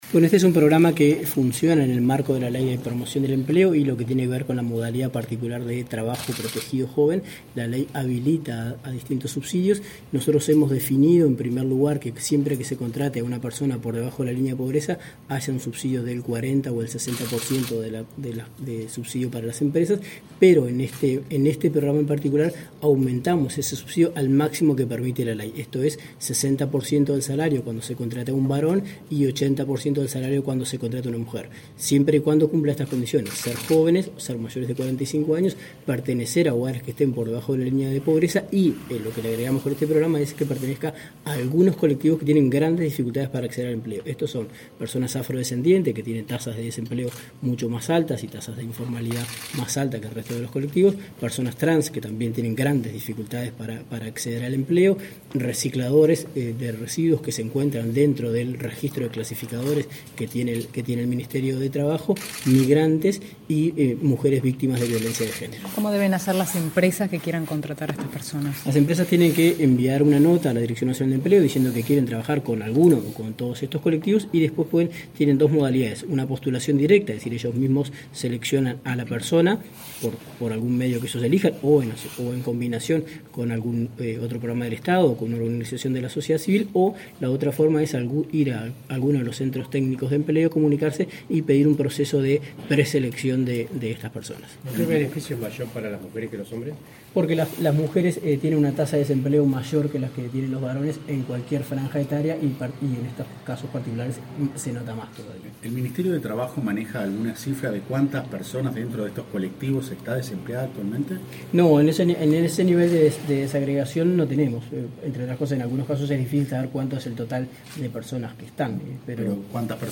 Declaraciones del director nacional de Empleo
El director nacional de Empleo, Daniel Pérez, explicó a la prensa el alcance del programa Colectivos Vulnerables, presentado este jueves 29 en el